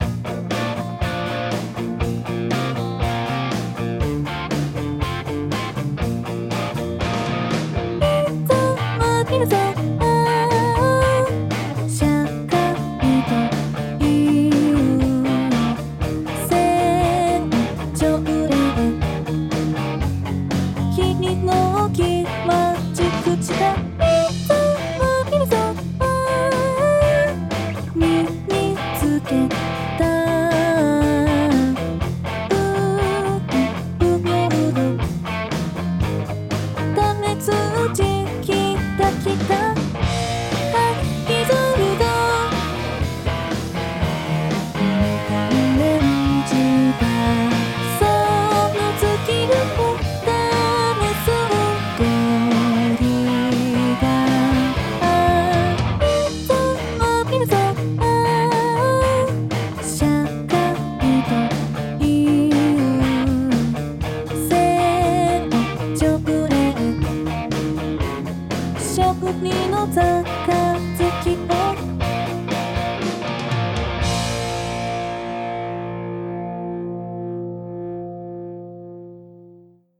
歌(104曲)